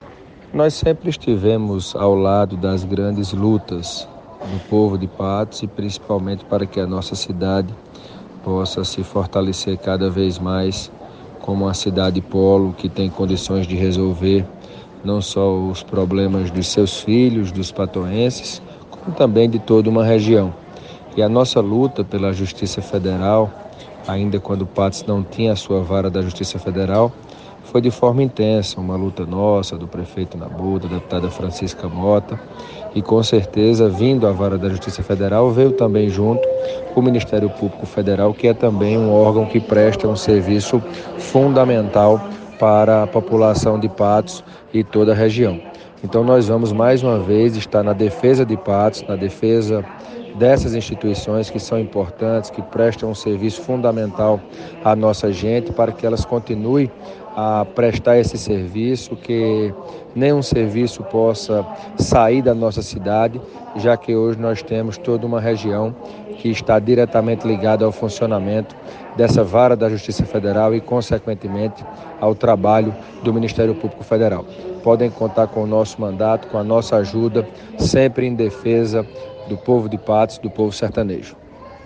conversou com o deputado federal Hugo Motta (Republicanos) sobre o possível fim do Ministério Público Federal em Patos.